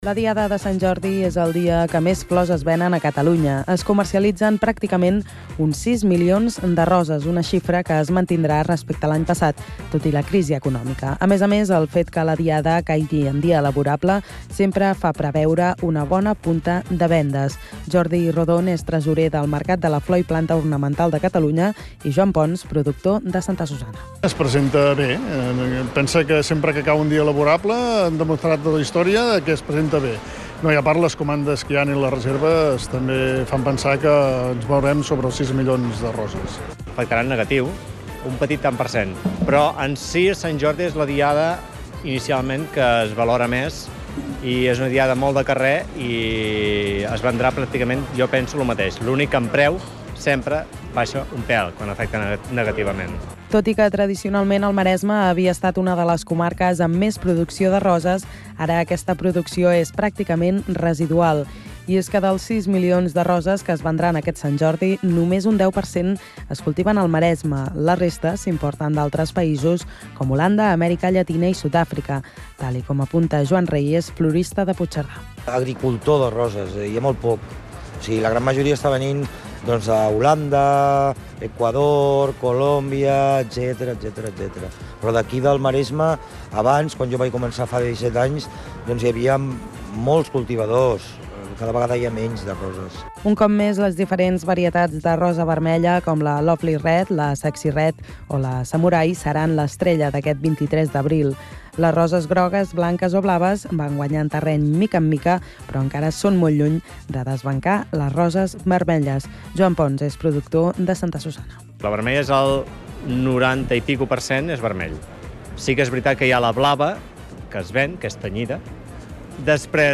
Crònica sobre la producció i venda de roses al Maresme per Sant Jordi
Informatiu